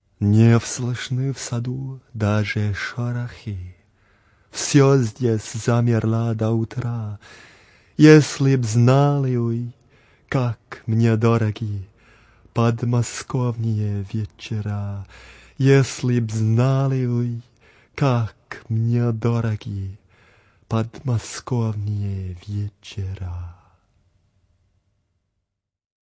Reading 6: